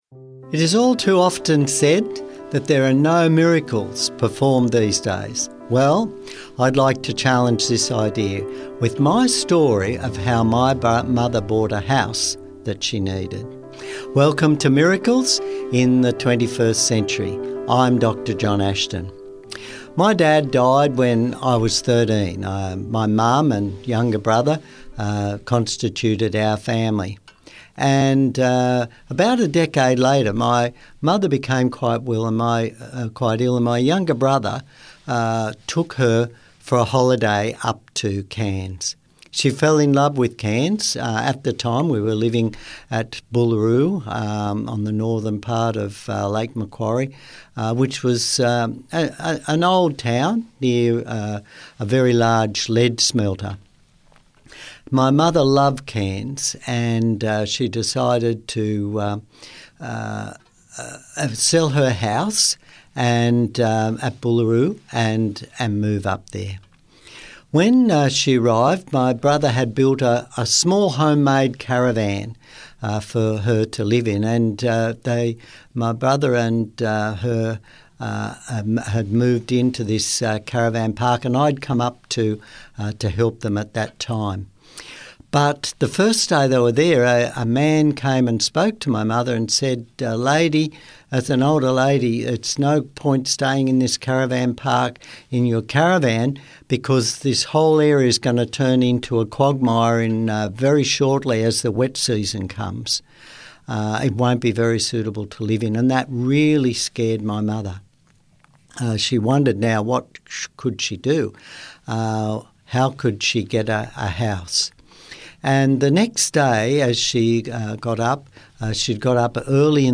Miracles? In the 21st Century? Yes, listen to people share real life miracle stories, not only from recent times but also amazing stories from their past.
Music Credits: